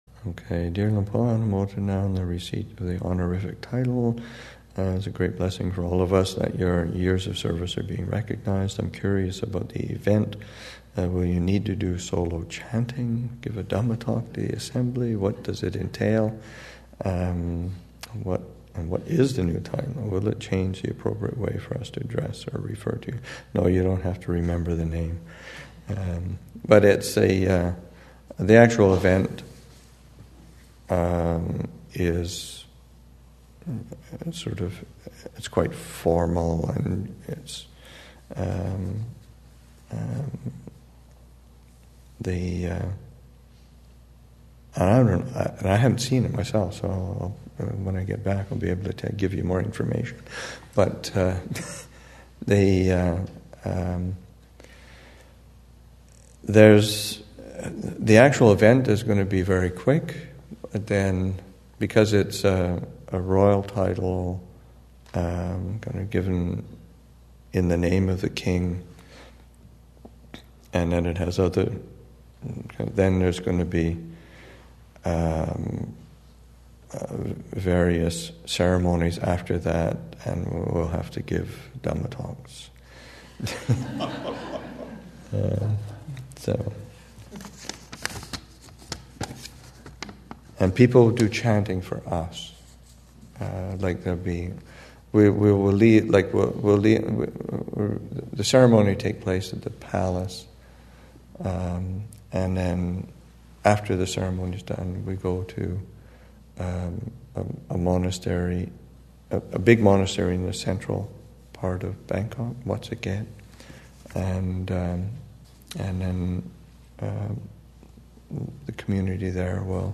2015 Thanksgiving Monastic Retreat, Session 8 – Nov. 28, 2015